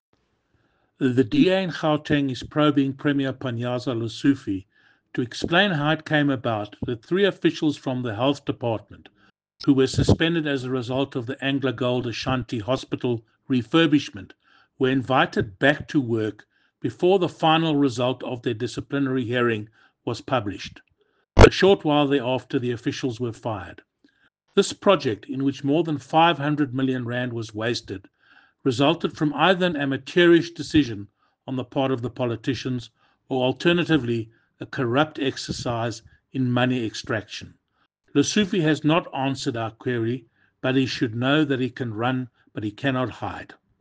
English soundbite by Alan Fuchs MPL.